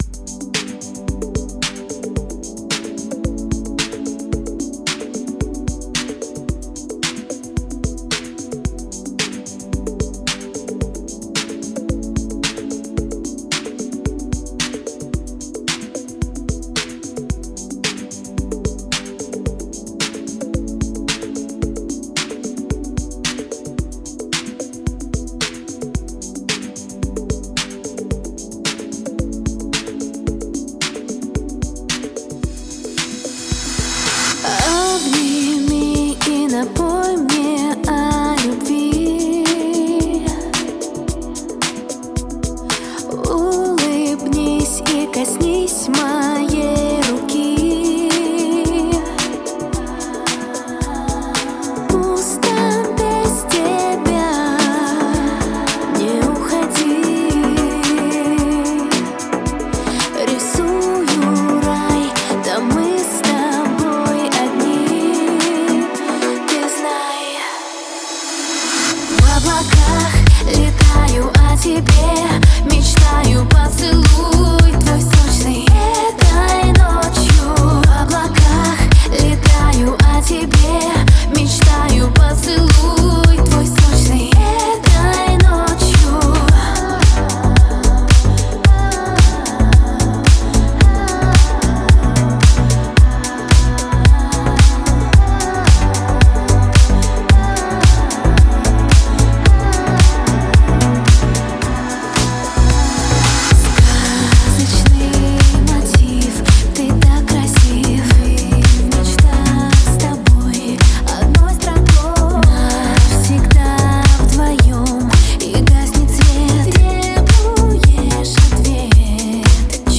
это трек в жанре deep house